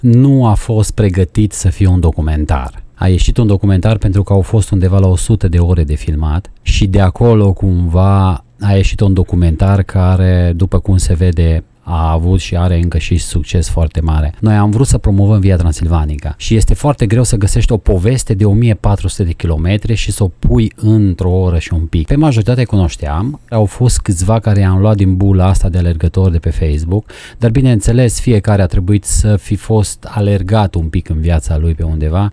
Invitat la Radio Cluj